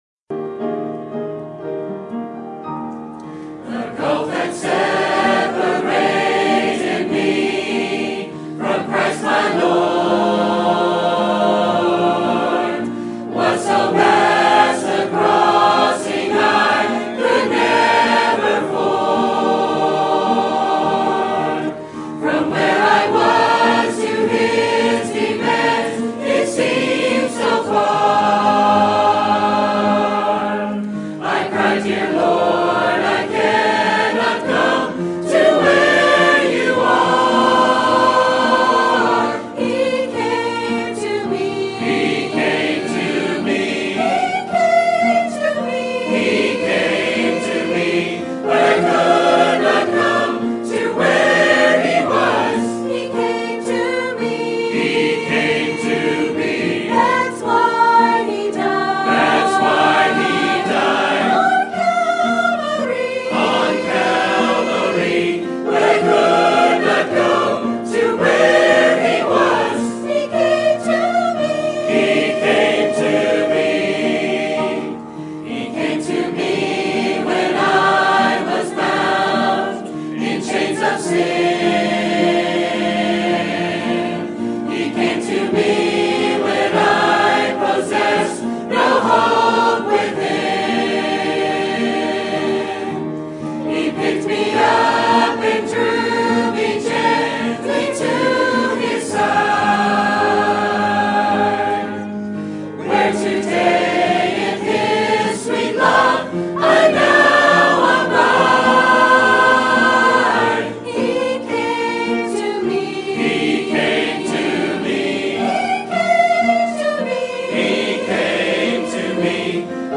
Sermon Topic: Life of Kings and Prophets Sermon Type: Series Sermon Audio: Sermon download: Download (28.04 MB) Sermon Tags: 1 Kings Kings Prophets Ahab